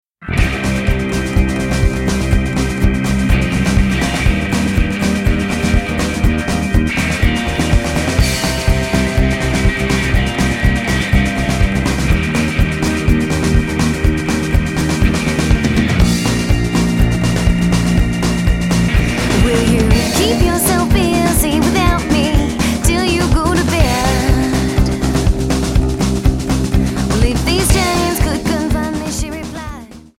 Alternative,Folk,Rock